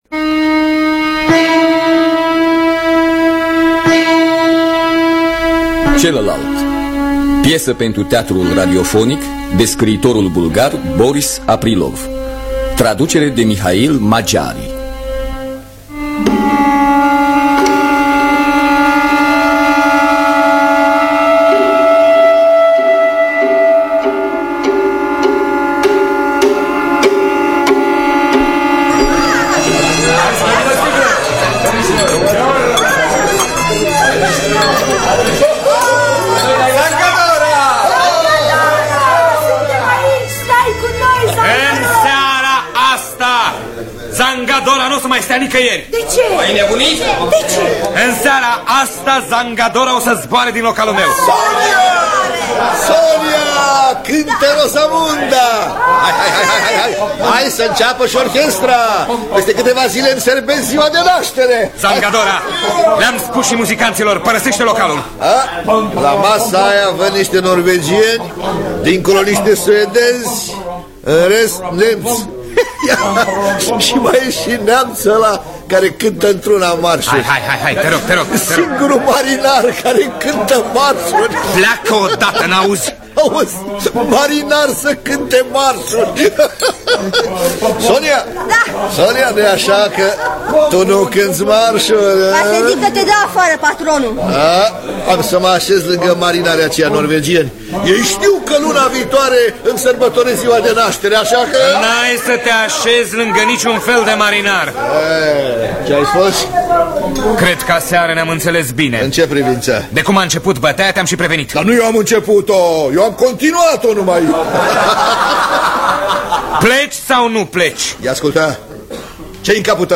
Celălalt de Boris Aprilov – Teatru Radiofonic Online